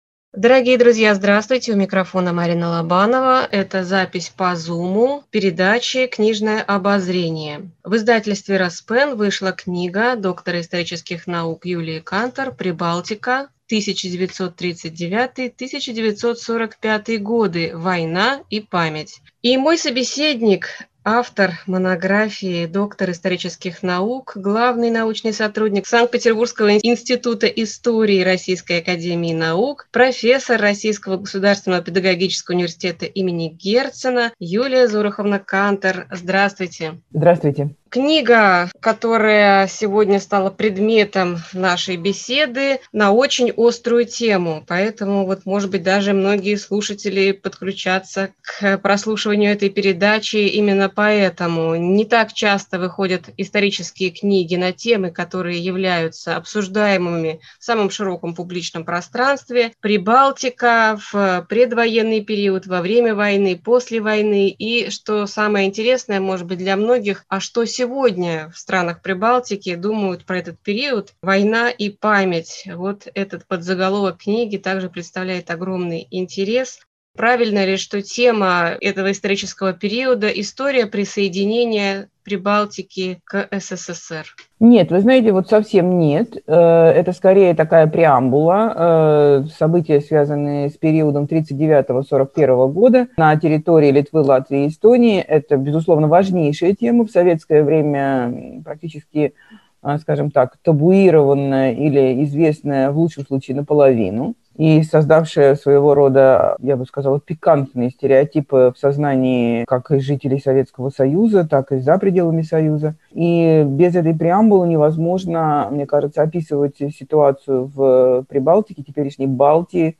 Книжное обозрение